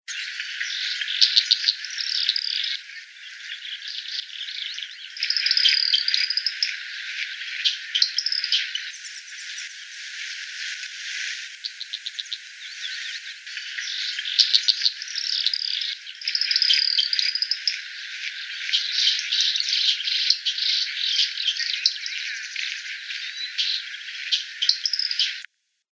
Fork-tailed Flycatcher (Tyrannus savana)
Sex: Male
Life Stage: Adult
Location or protected area: Reserva Ecológica Costanera Sur (RECS)
Condition: Wild
Certainty: Recorded vocal